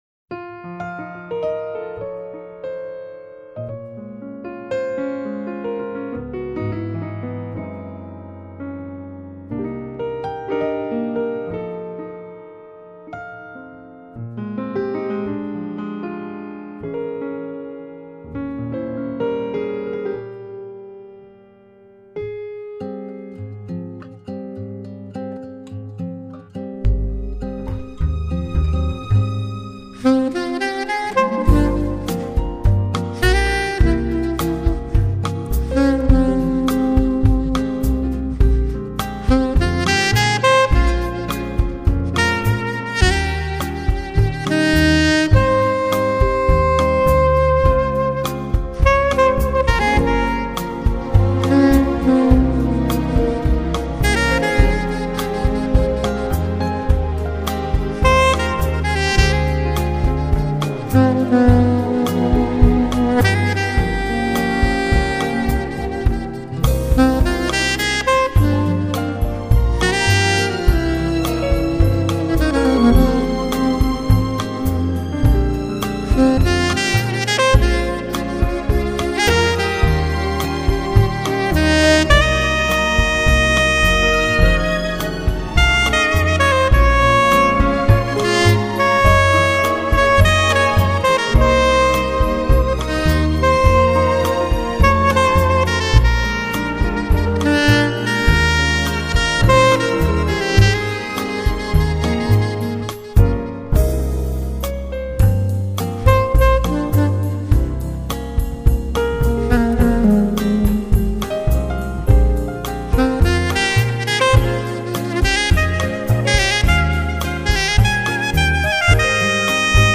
专辑语种：纯音乐专辑1CD
以最浪漫的音符
（低品质）